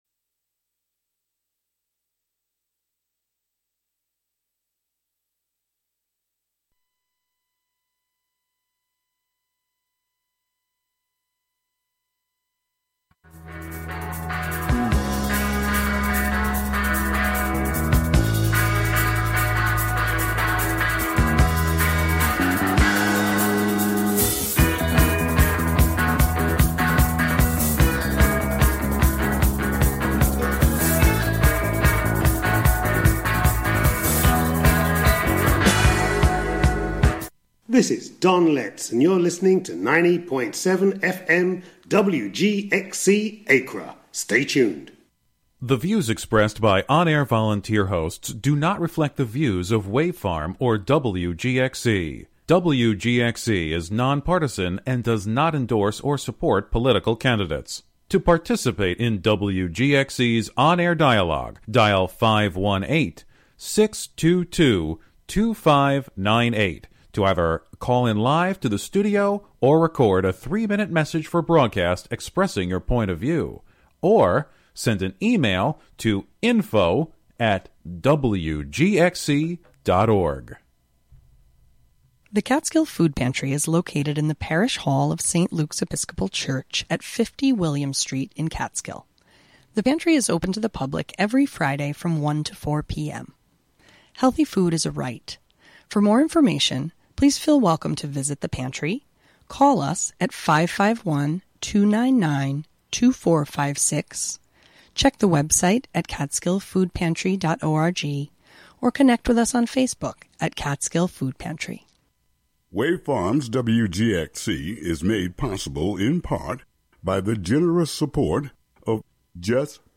7pm "Foraging Ahead" features music and interviews fro...
broadcast live from WGXC's Catskill studio.